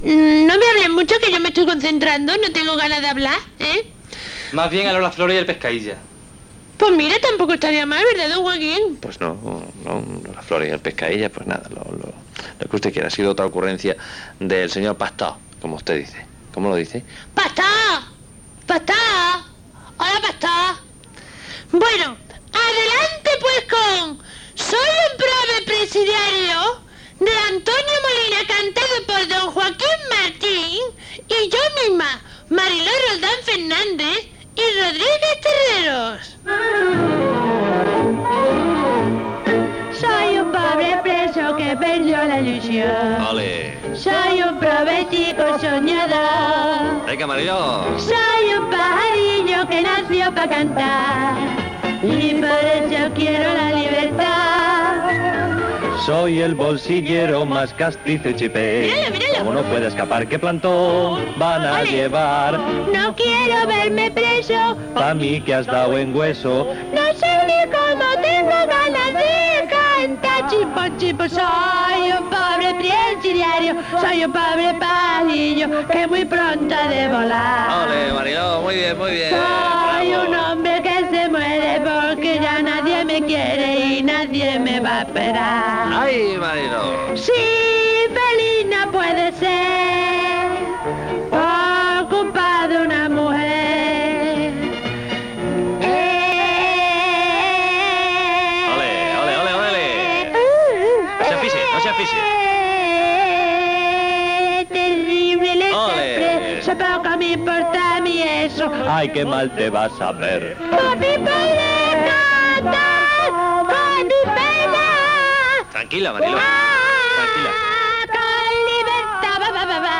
Interpretació d'una copla
Entreteniment